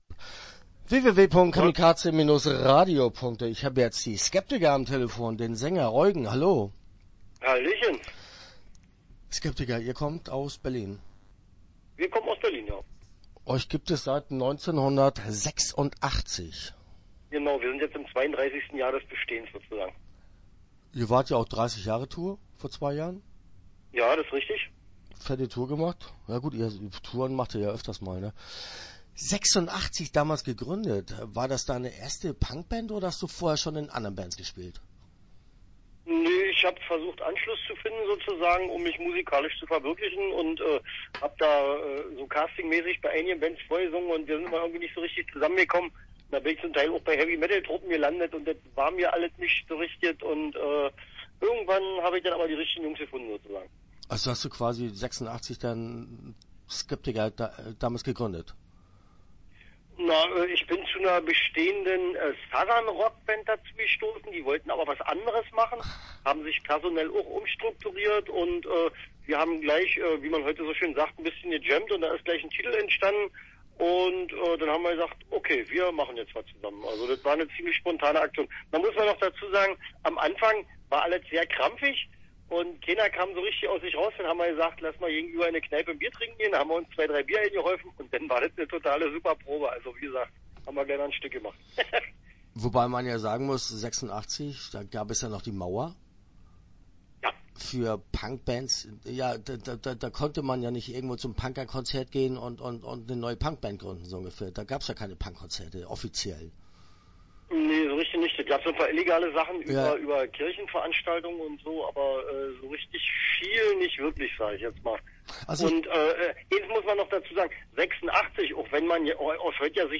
Start » Interviews » Die Skeptiker